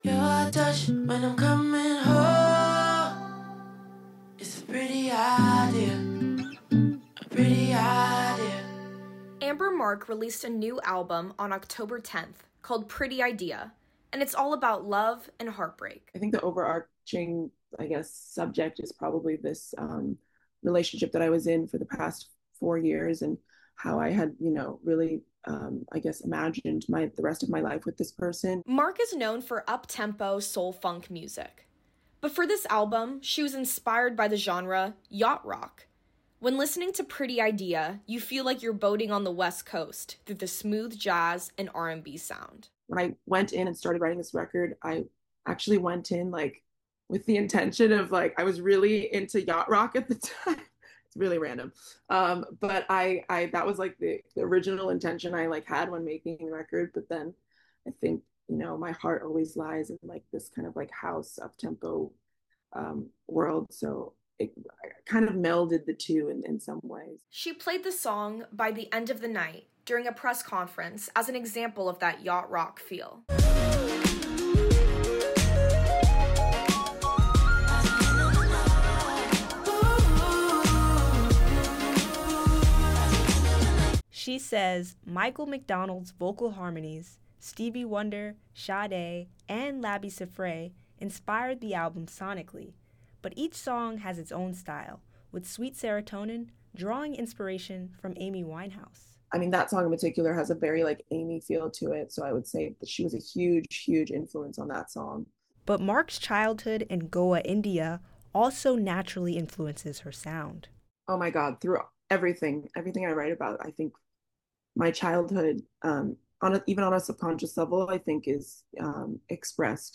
[“Pretty Idea” by Amber Mark, small expert ] Amber Mark released a new album on October 10th called “Pretty Idea,” and it’s all about love and heartbreak.